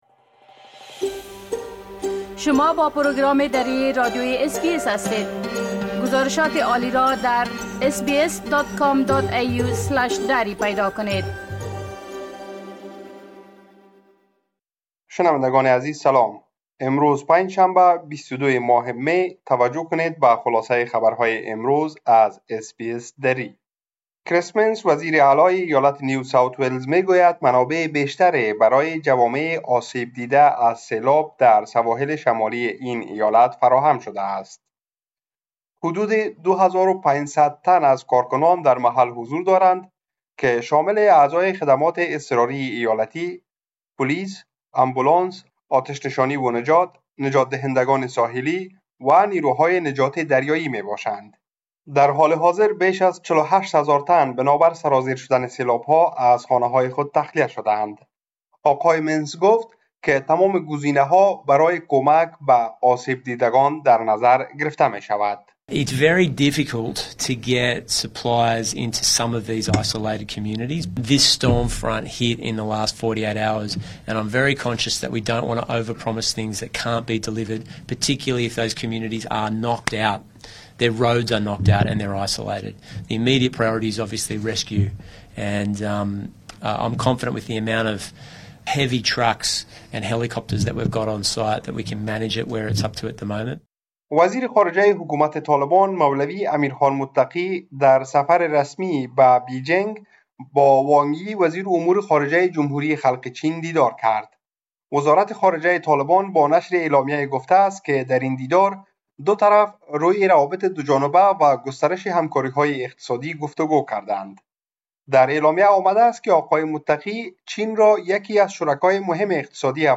خلاصه مهمترين اخبار روز از بخش درى راديوى اس بى اس| ۲۲ می ۲۰۲۵